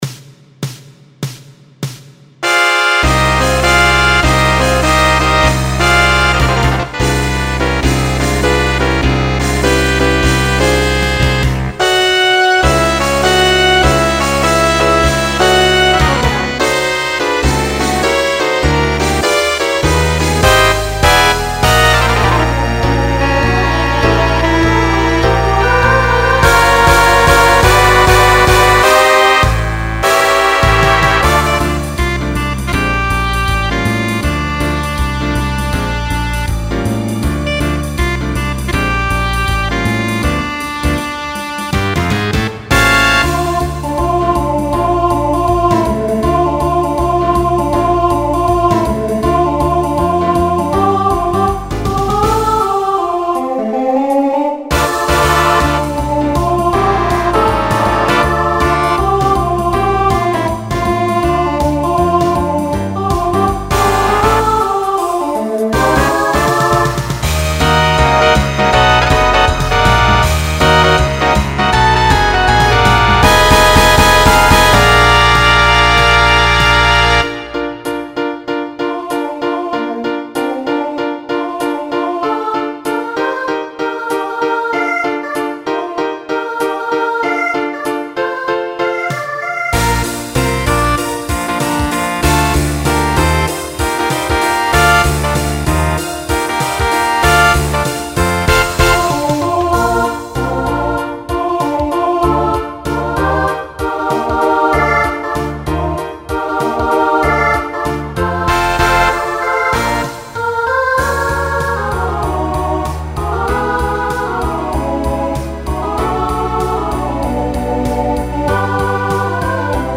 Genre Broadway/Film Instrumental combo
Voicing SSA